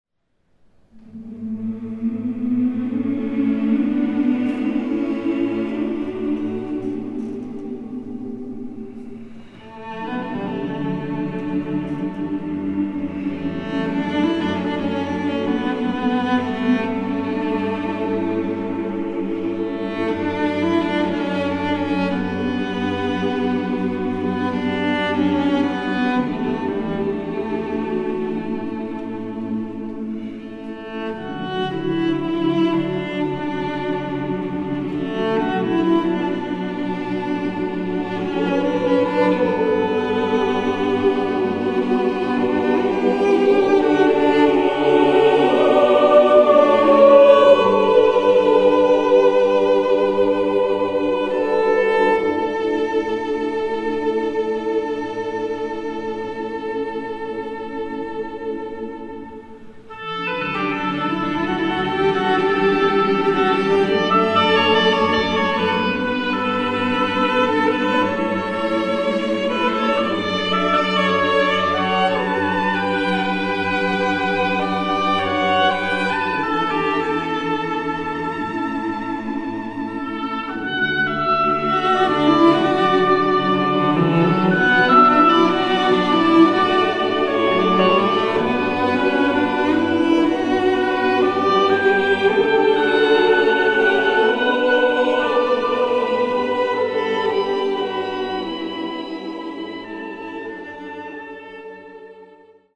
Voicing: SATB, Oboe and Cello